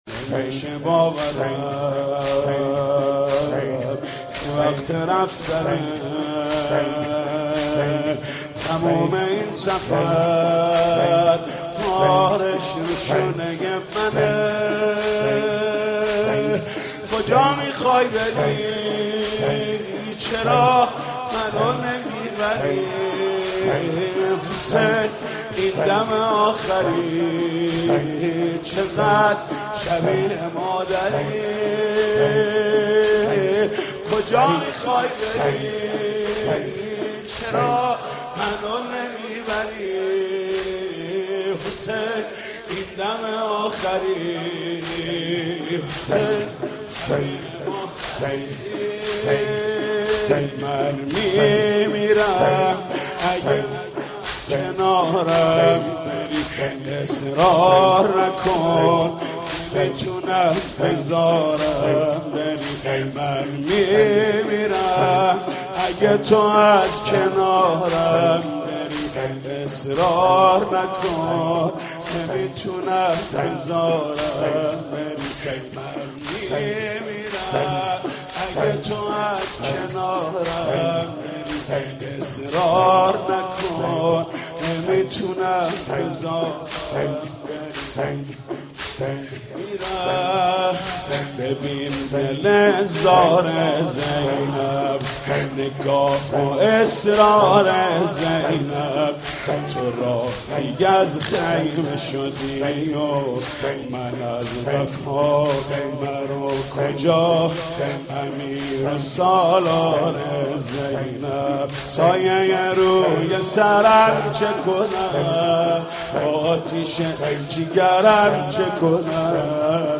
سینه زنی؛ «بچه هام مثل خودم حسین، برای تو میمیرند حسین» با مداحی «محمود کریمی»